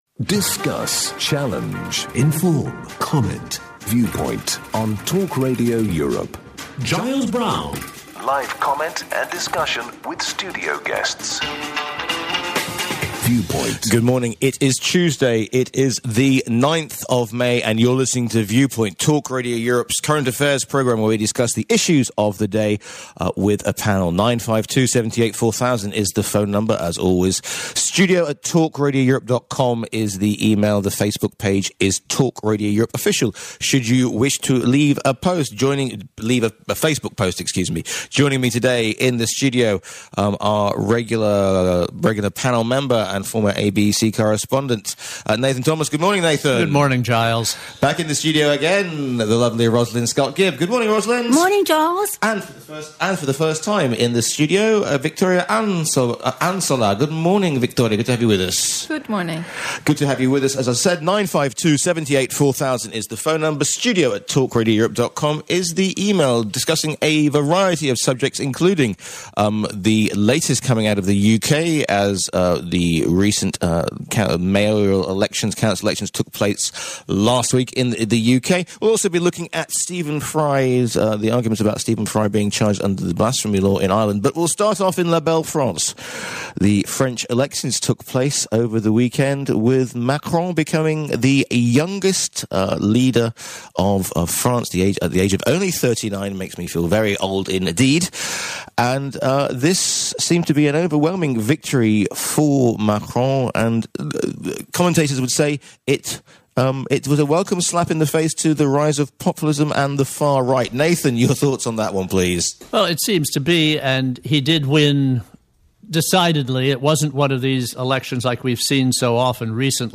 The panel discuss the major and breaking news stories of the week. The show is uncensored, often controversial, and full of heated debate.